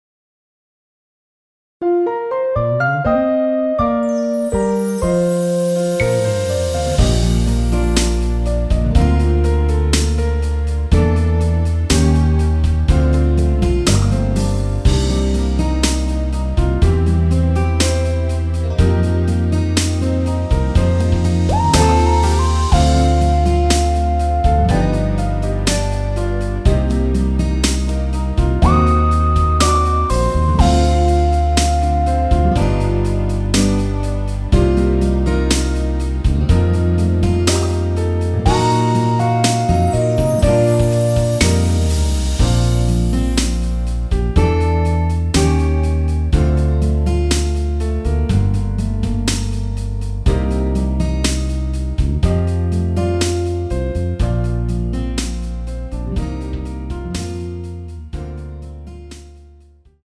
원키에서(+2)올린 MR입니다.
Bb
앞부분30초, 뒷부분30초씩 편집해서 올려 드리고 있습니다.
중간에 음이 끈어지고 다시 나오는 이유는